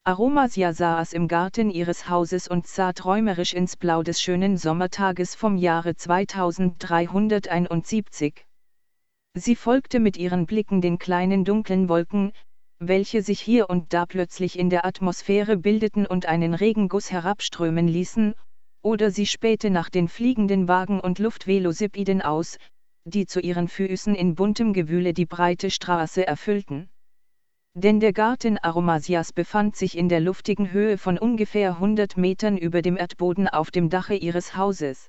Sprachsynthese
Seit Windows XP gibt es eine eingebaute Sprachsynthese auf die Balabolka zurückgreifen kann. Auf meinem Rechner ist beispielsweise eine deutsche Stimme (Microsoft Hedda Desktop) und eine mit amerikanischem Englisch (Microsoft Zira Desktop) vorinstalliert.